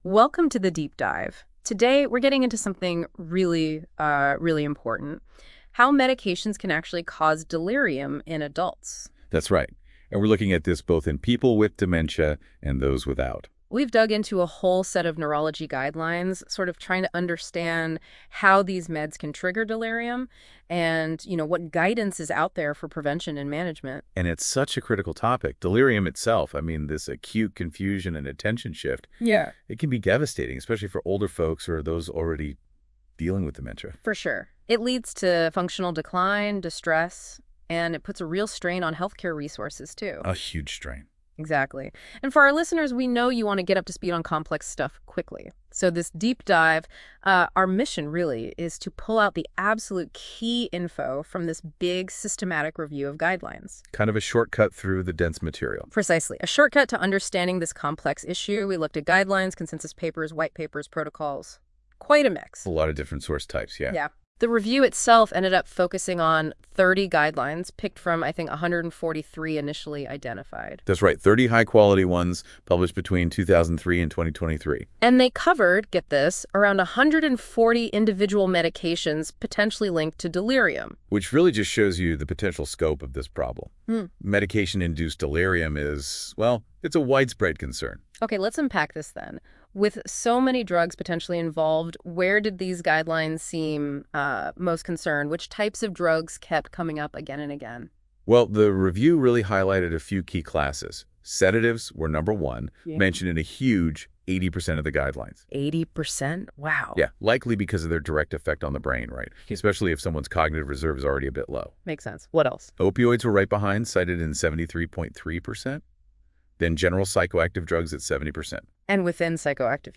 Sample our research via our AI deep-dive, generated by NotebookLM. All podcasts have been generated using the the original source publication and it's sources as context.